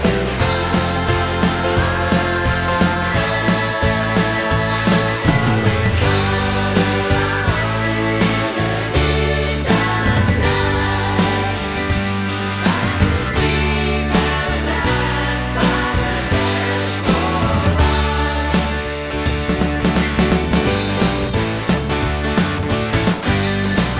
One Semitone Down Rock 7:34 Buy £1.50